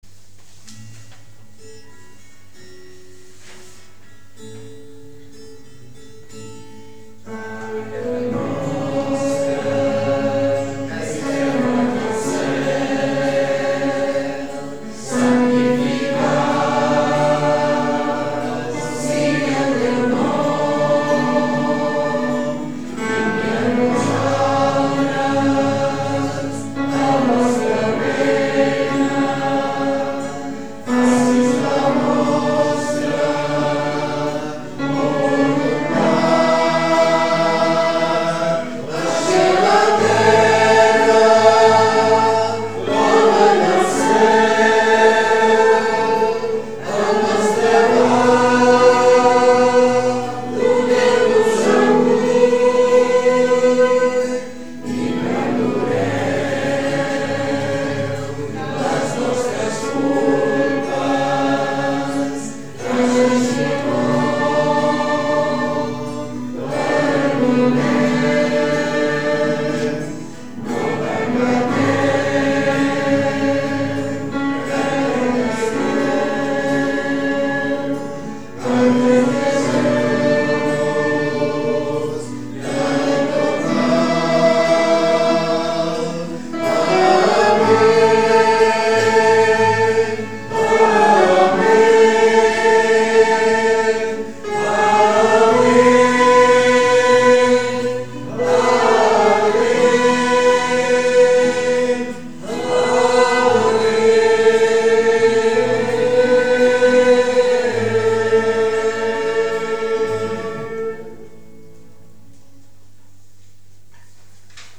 Pregària de Taizé
Capella de les Concepcionistes de Sant Josep - Diumenge 24 de novembre de 2013